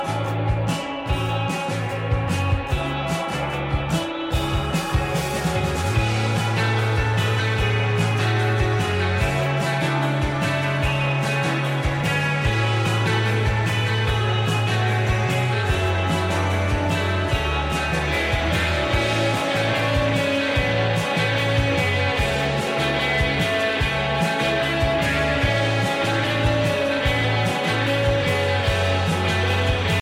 Indie pop , Indie rock